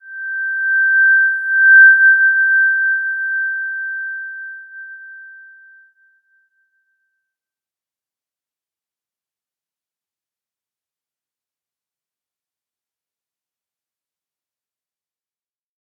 Slow-Distant-Chime-G6-p.wav